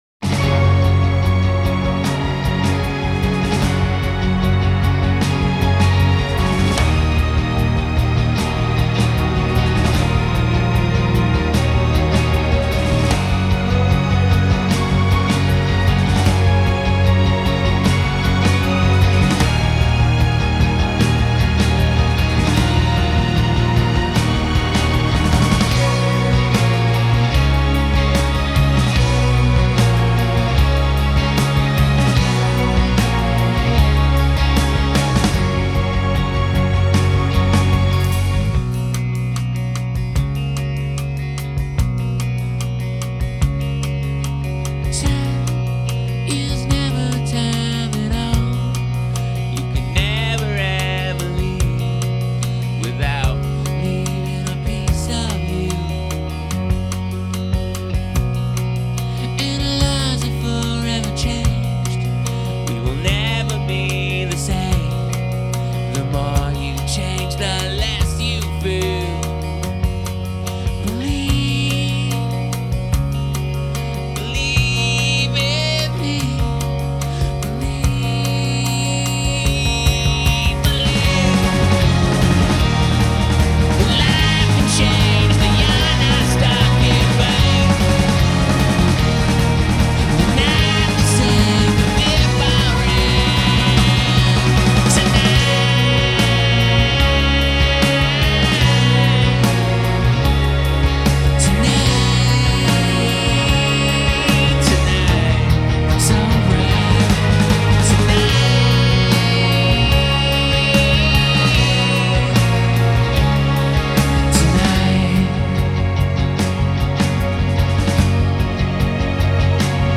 американская группа альтернативного рока